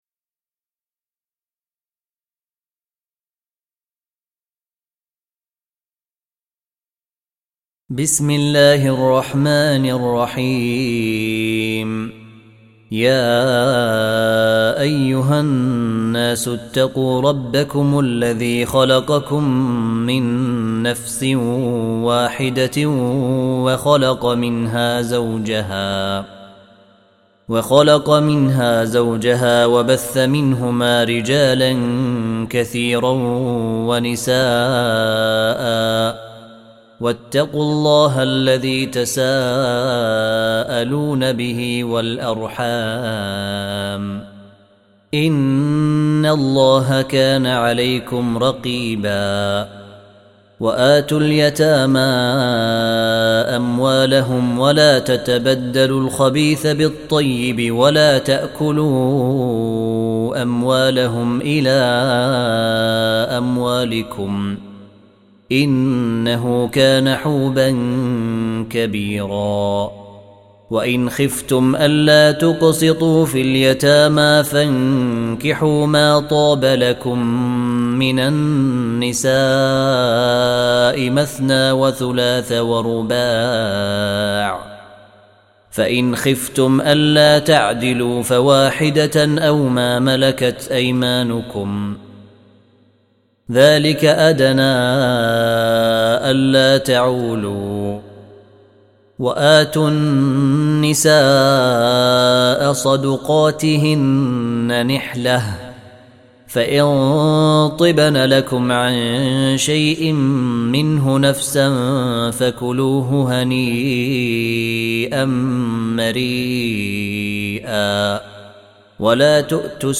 4. Surah An-Nis�' سورة النساء Audio Quran Tarteel Recitation
Surah Sequence تتابع السورة Download Surah حمّل السورة Reciting Murattalah Audio for 4. Surah An-Nis�' سورة النساء N.B *Surah Includes Al-Basmalah Reciters Sequents تتابع التلاوات Reciters Repeats تكرار التلاوات